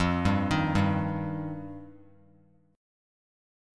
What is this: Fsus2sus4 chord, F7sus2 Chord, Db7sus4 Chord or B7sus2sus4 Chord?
F7sus2 Chord